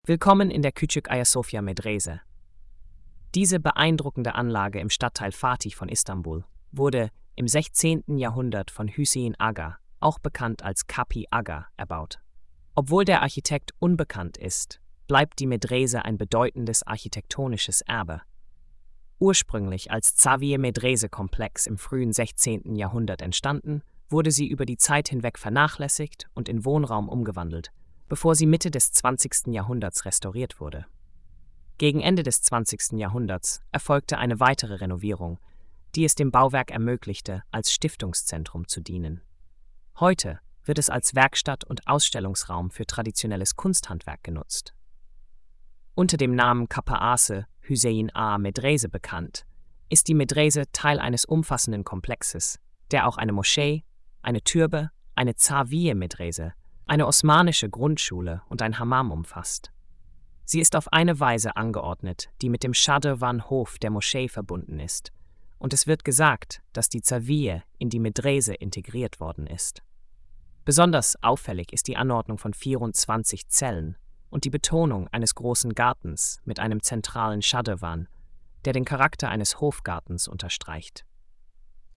HÖRFASSUNG DES INHALTS: